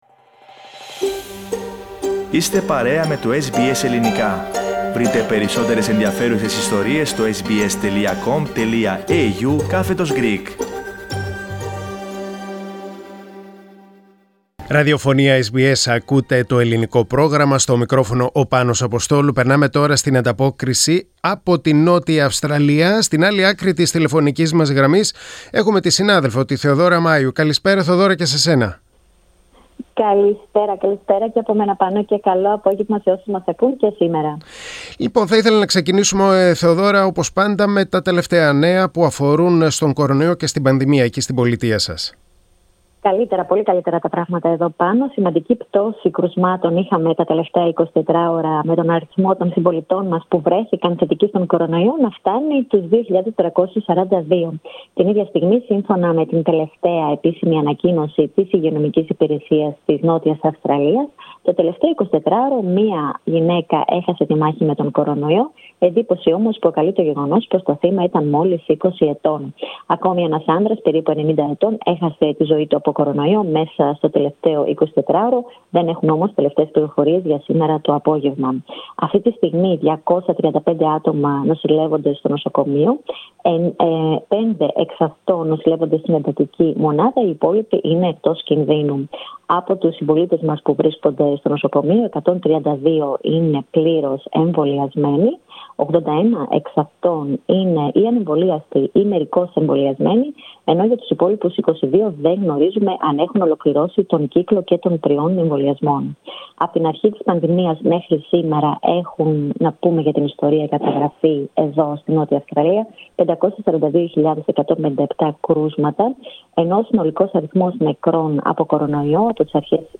Η εβδομαδιαία ανταπόκριση από την Νότια Αυστραλία.